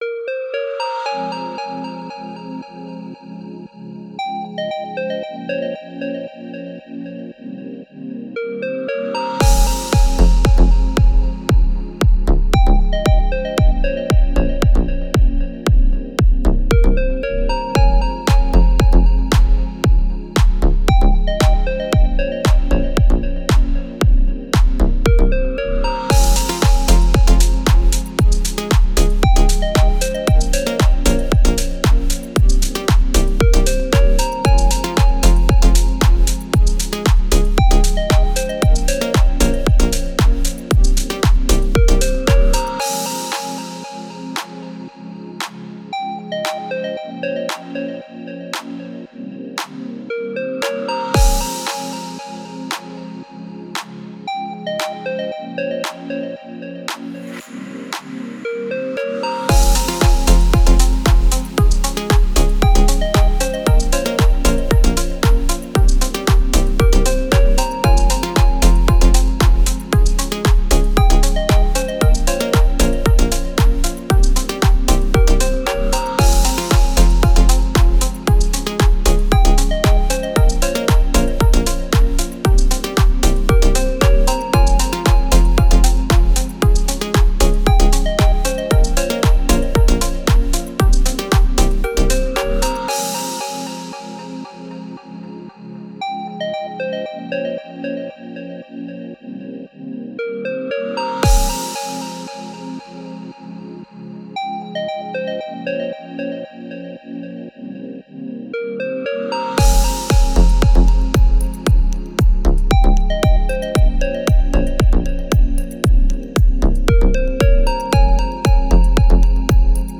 Deep House музыка
музыка без слов